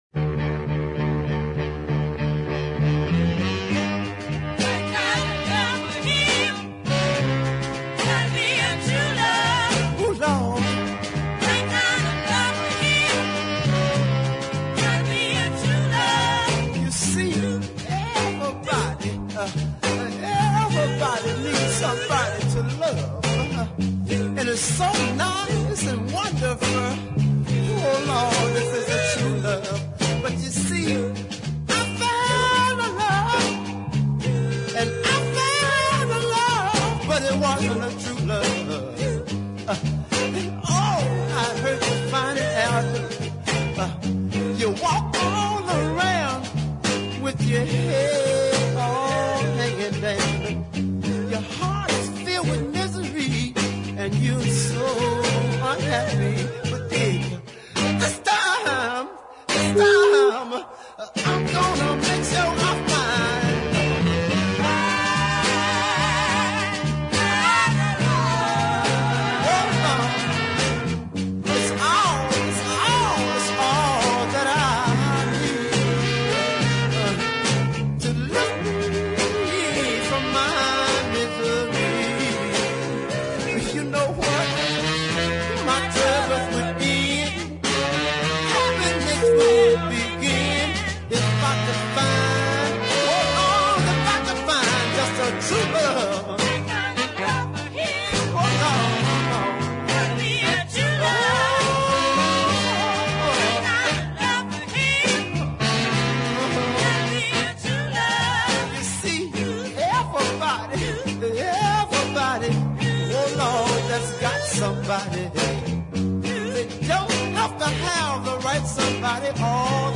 bluesy deep soul